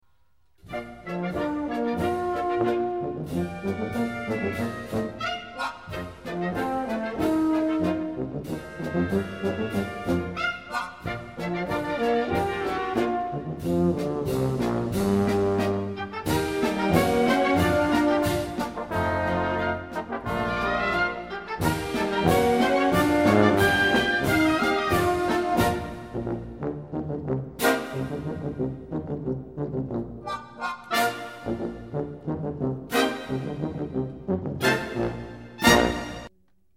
Gattung: Solo für diverse Instumente und Blasorchester
Besetzung: Blasorchester
Lustiges Musikstück für vier Posaunen und zwei Tuben.